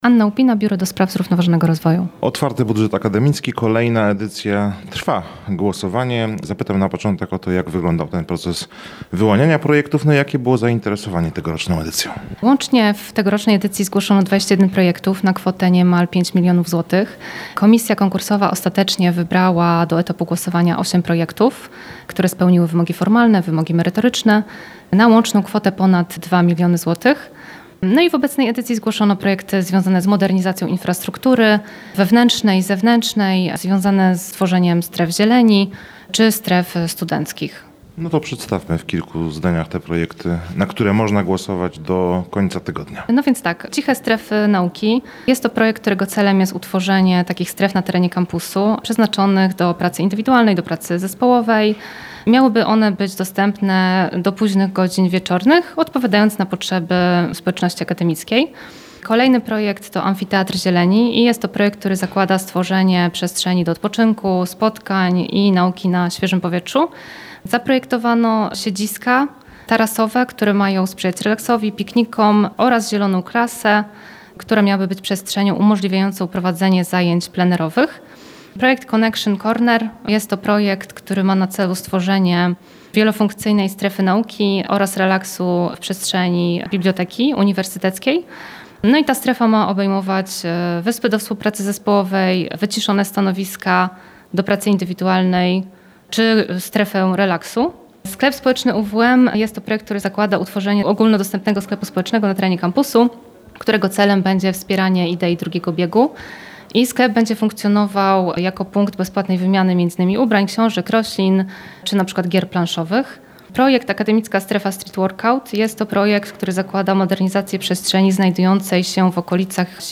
rozmawiał